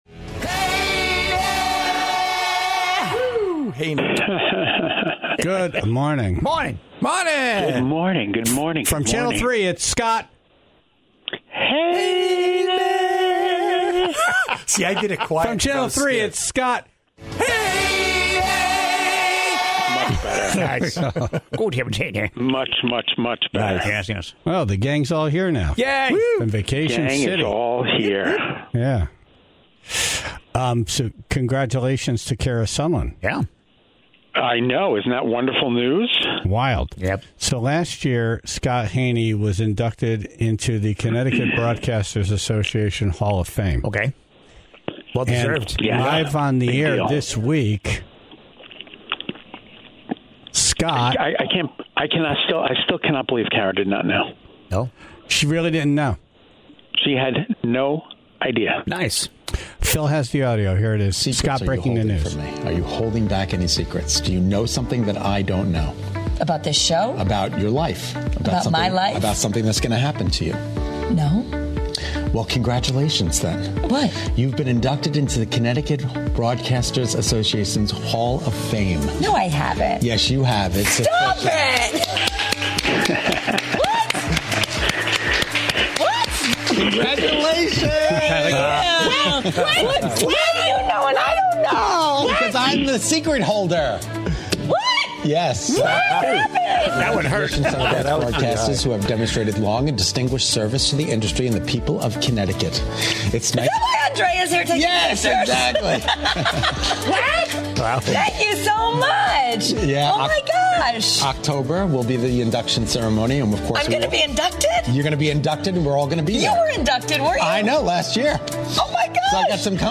The Tribe called in their most outlandish stories of injuries, prison time, and bank robberies.